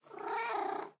Minecraft Version Minecraft Version snapshot Latest Release | Latest Snapshot snapshot / assets / minecraft / sounds / mob / cat / purreow2.ogg Compare With Compare With Latest Release | Latest Snapshot
purreow2.ogg